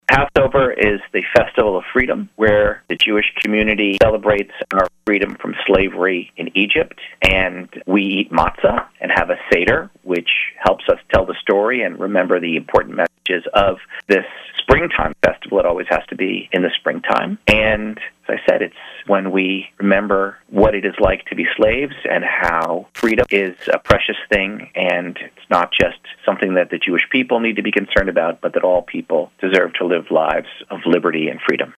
Local Rabbi Says Passover Has More Somber Tone This Year